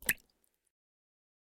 5caee9fba5 Divergent / mods / Bullet Shell Sounds / gamedata / sounds / bullet_shells / generic_water_4.ogg 18 KiB (Stored with Git LFS) Raw History
generic_water_4.ogg